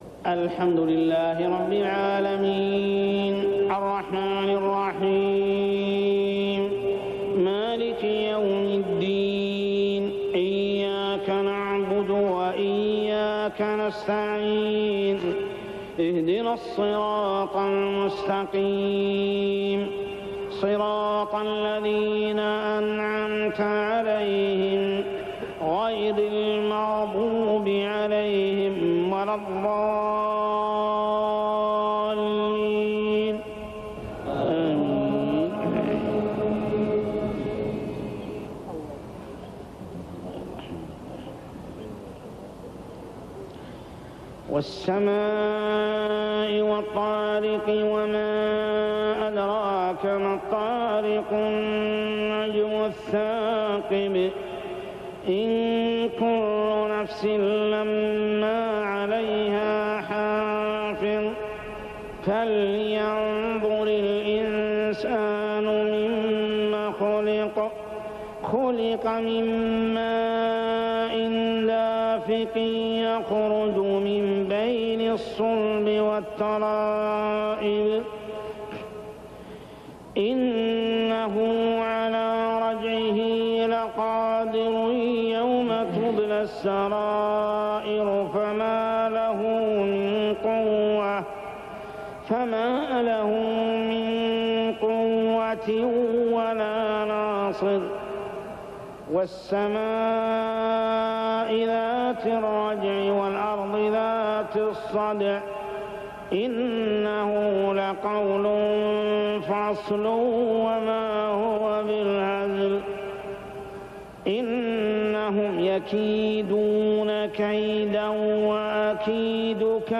صلاة العشاء عام 1407هـ سورتي الطارق و التين كاملة | Isha prayer Surah At-Tariq and At-Tin > 1407 🕋 > الفروض - تلاوات الحرمين